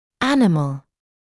[‘ænɪml][‘энимл]животное; животный, относящийся к животному миру